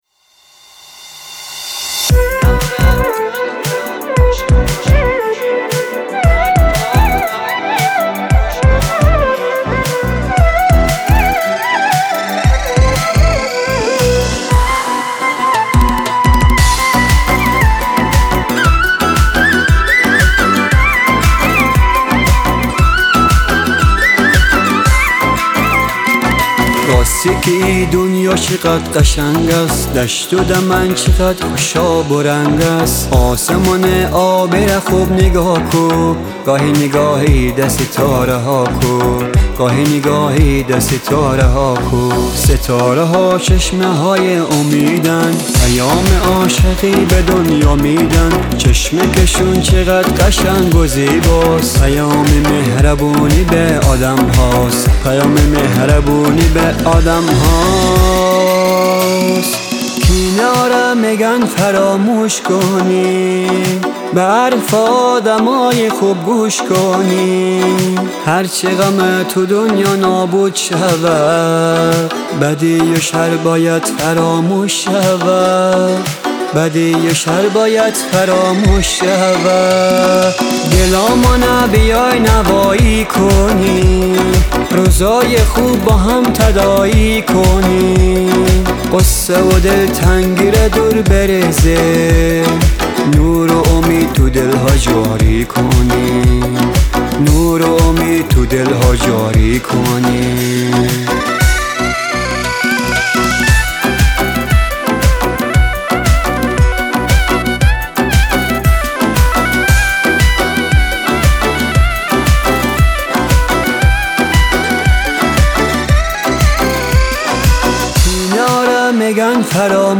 Afghani Music